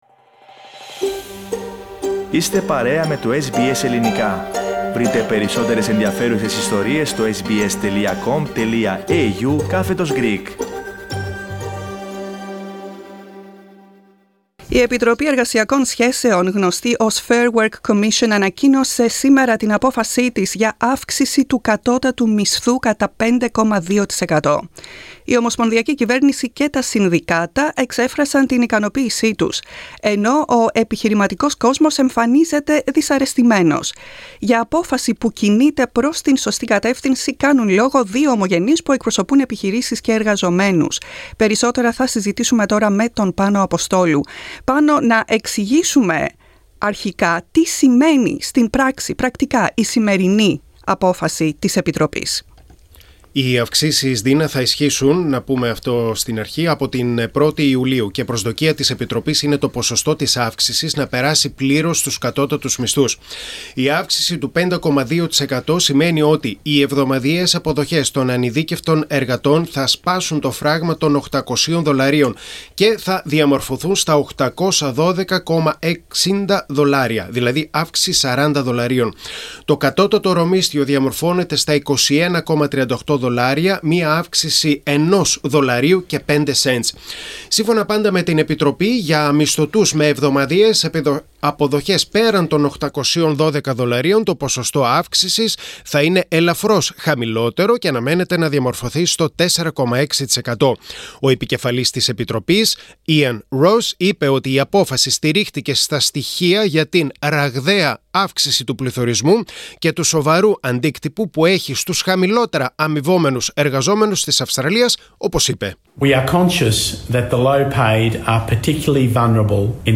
Αύξηση κατώτατου μισθού στην Αυστραλία: Πώς σχολιάζουν δύο Ελληνο-Αυστραλοί;